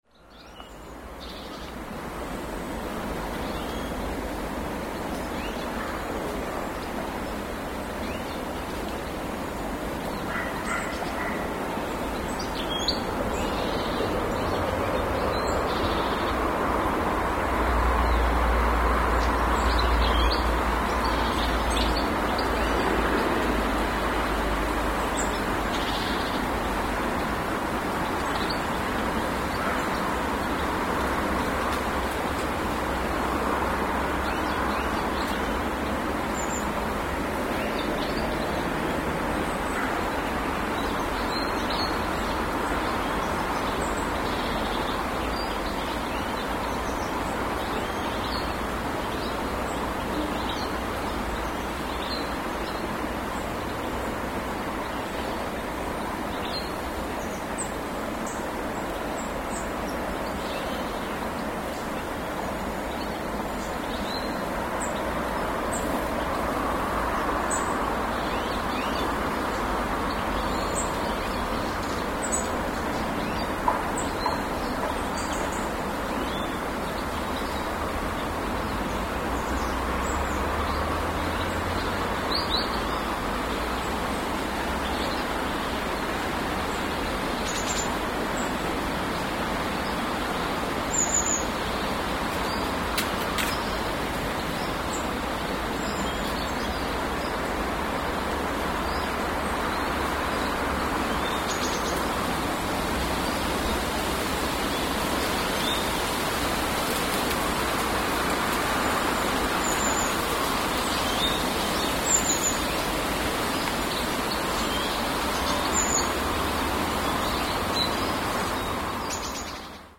Goldfinch flock feeding near the old shooting range